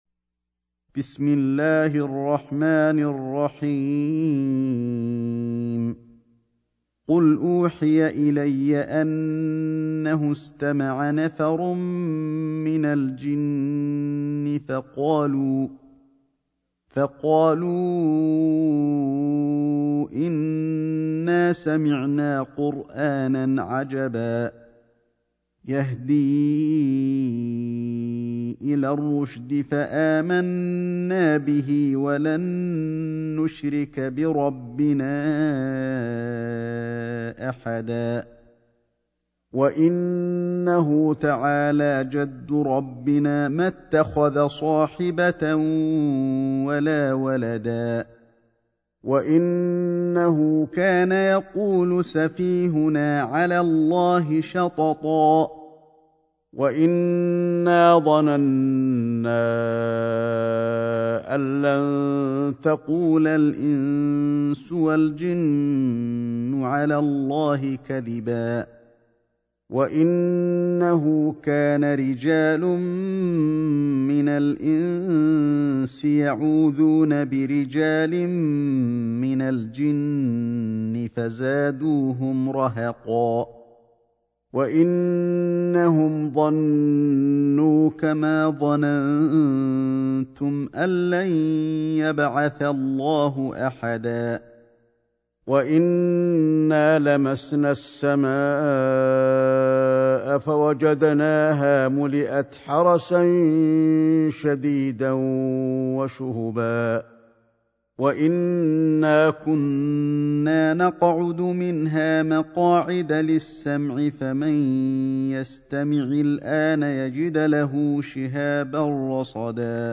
سورة الجن > مصحف الشيخ علي الحذيفي ( رواية شعبة عن عاصم ) > المصحف - تلاوات الحرمين